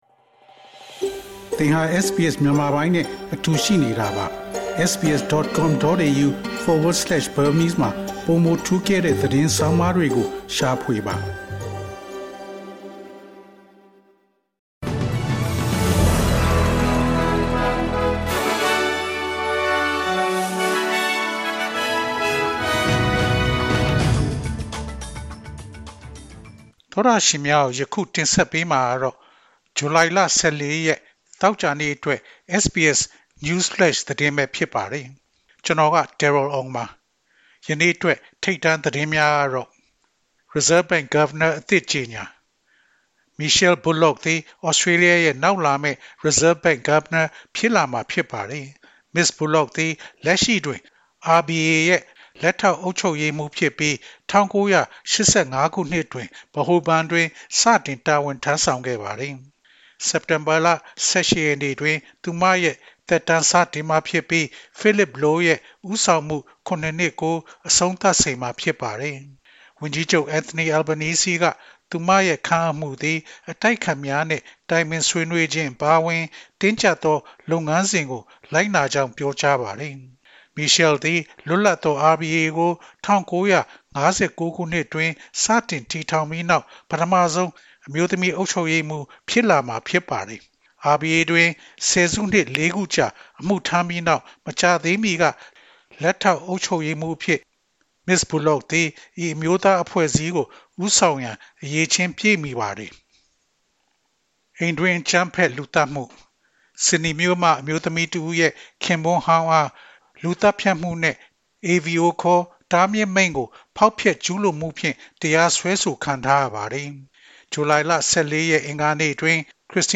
Burmese News Flash